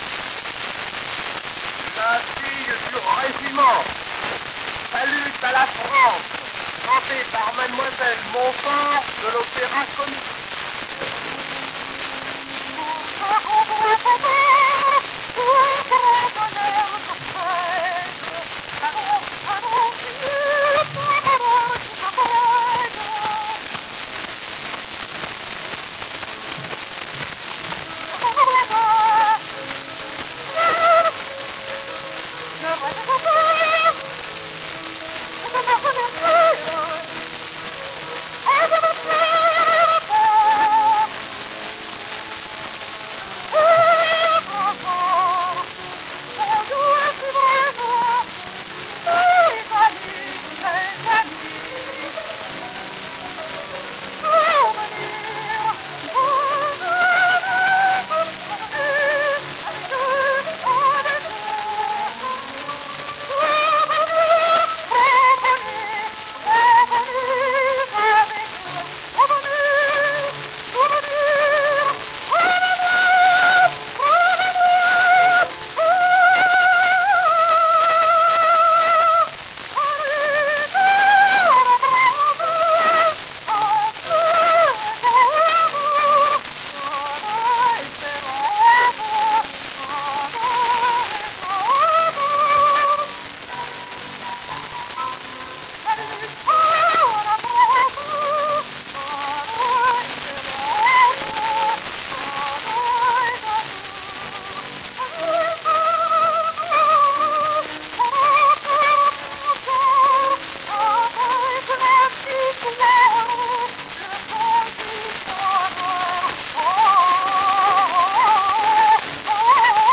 Salut à la France. Sopran mit Klavierbegleitung.